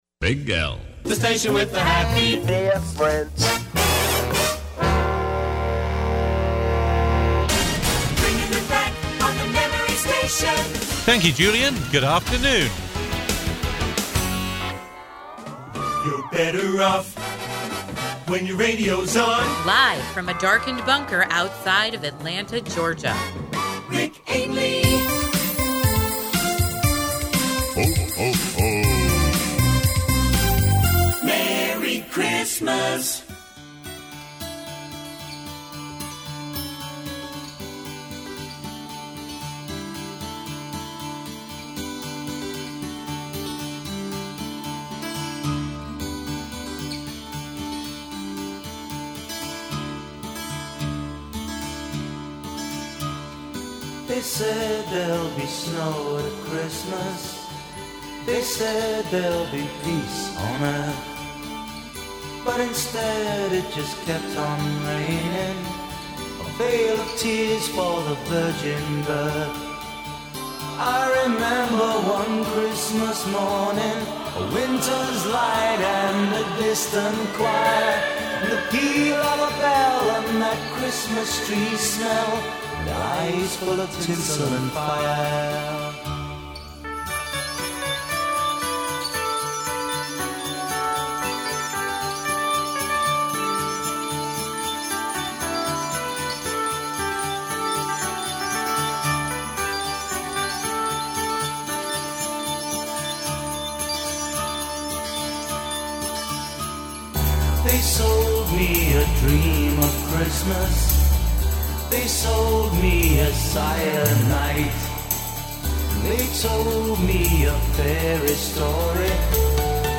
An hour of music, melodies and memories. Competitions too.